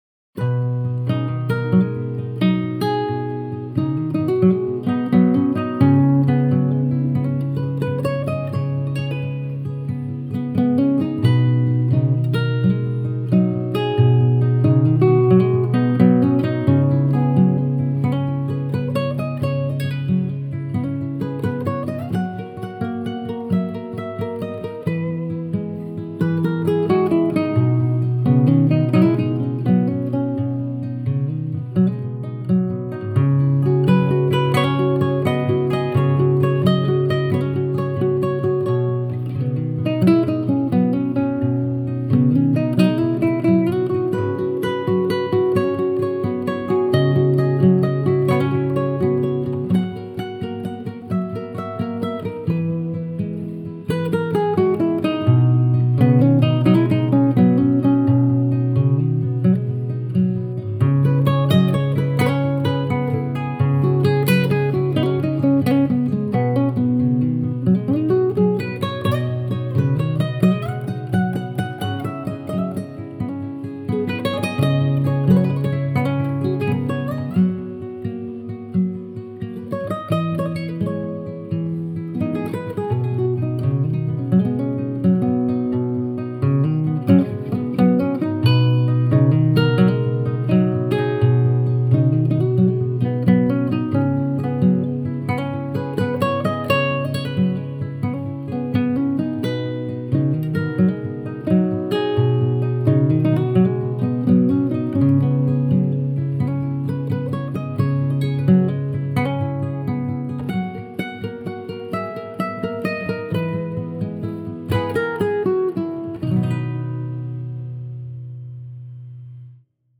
سبک آرامش بخش , امید‌بخش , گیتار , موسیقی بی کلام
گیتار آرامبخش